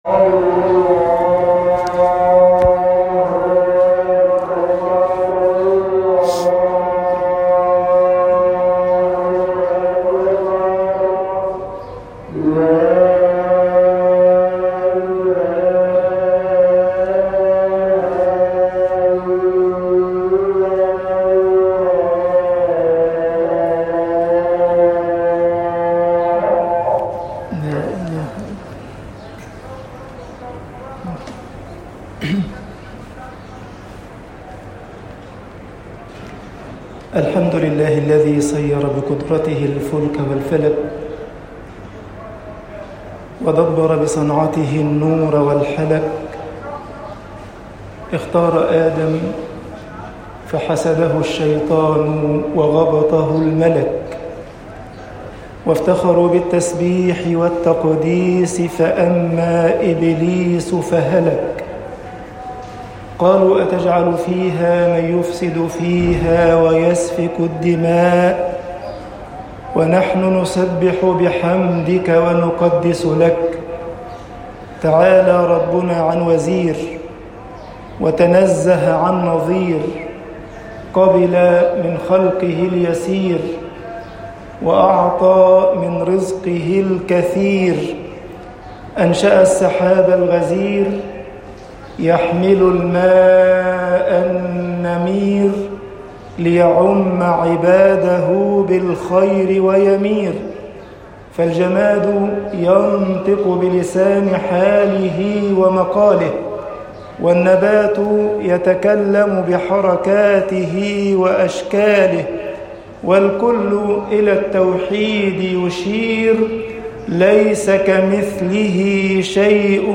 خطب الجمعة - مصر أمَّةُ الْإسْلَامِ أمَّةُ الْوَسَطِ طباعة البريد الإلكتروني التفاصيل كتب بواسطة